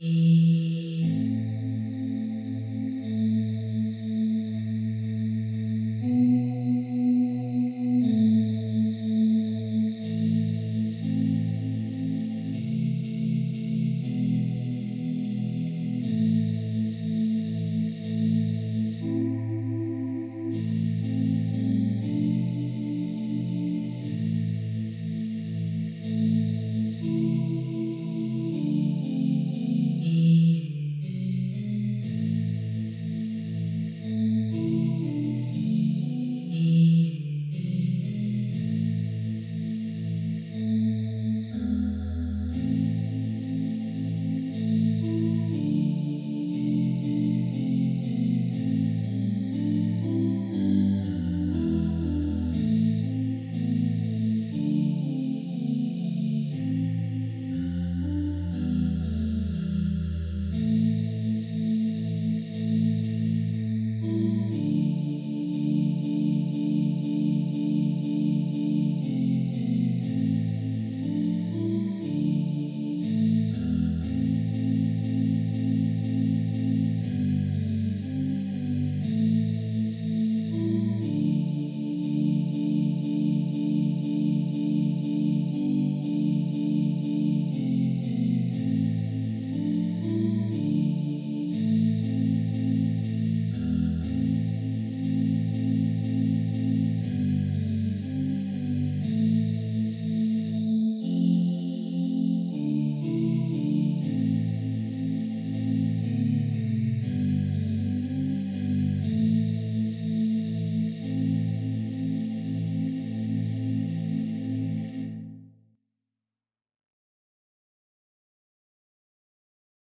Начало панихиды.
panyhida.mp3